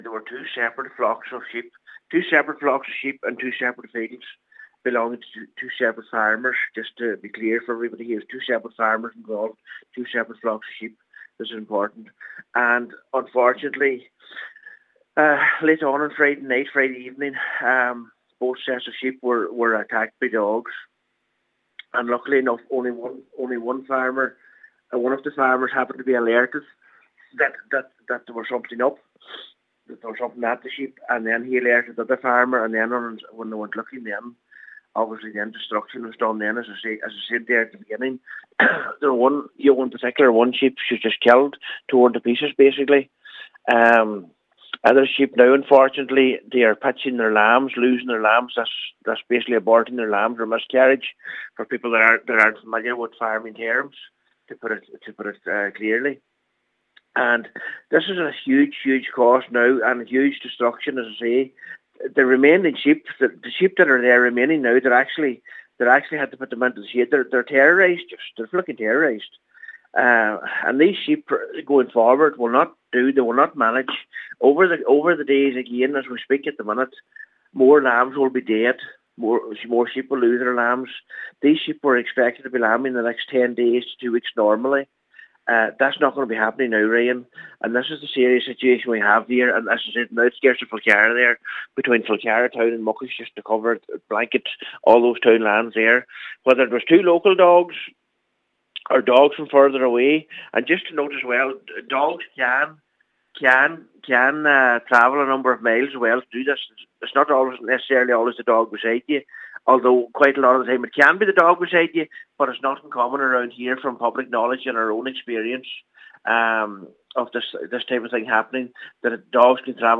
Local Cllr Michael McClafferty has raised the issue and urges dog owners to practice responsibility for their dogs: